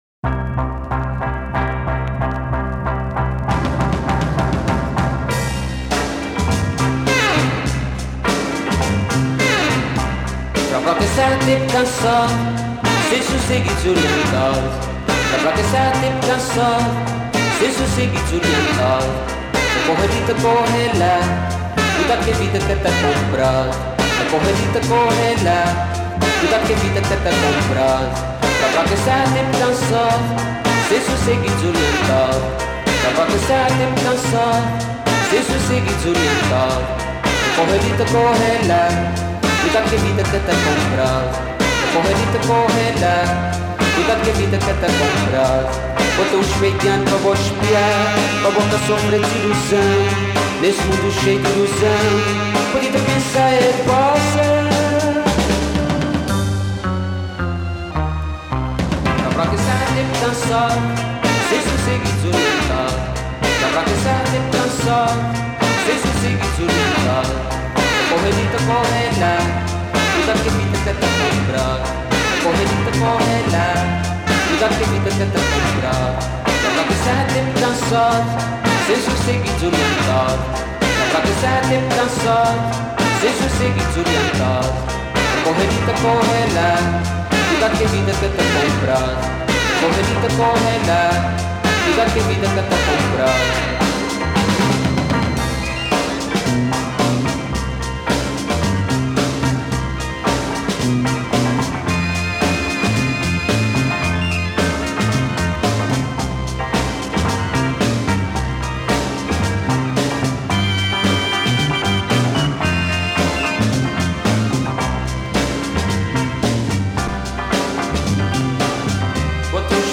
saxophone, clarinet
trumpet, vocals, bass guitar
drums